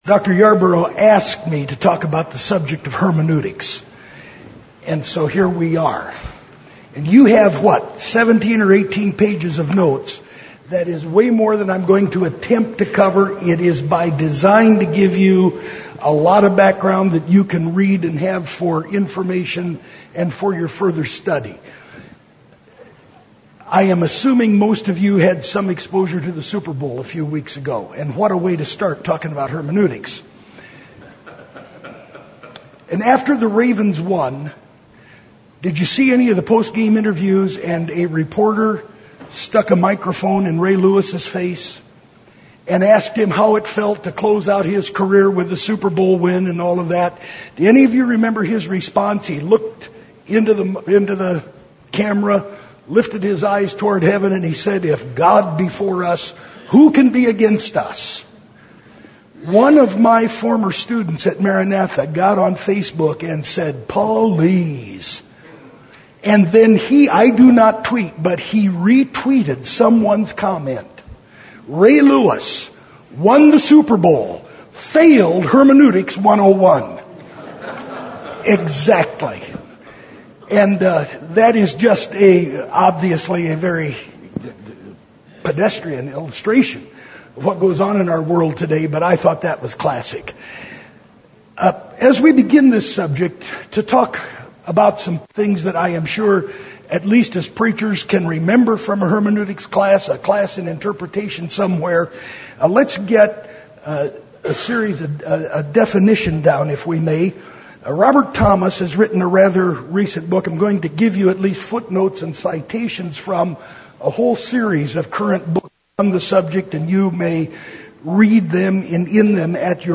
Series: FBFI South Regional Fellowship